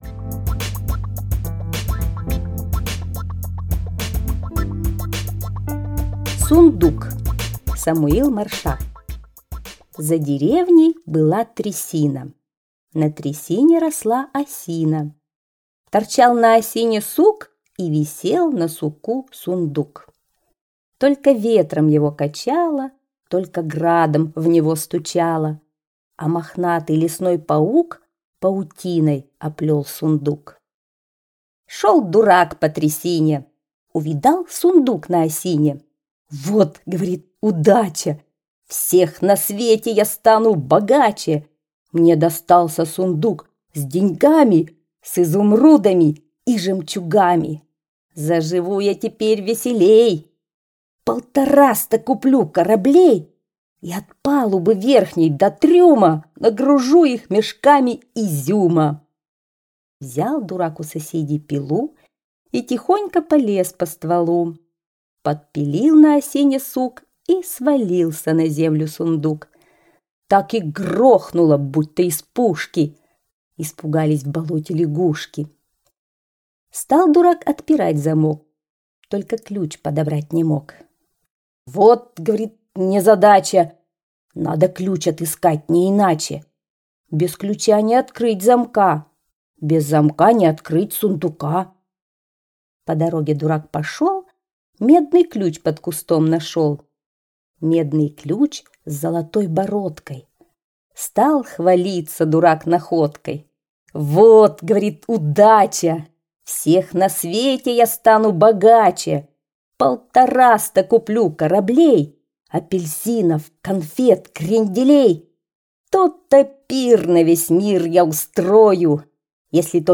Сундук - аудиосказка Маршака С.Я. Сказка о том, как дурак нашёл сундук, висящий на осине и сразу размечтался, что станет самым богатым.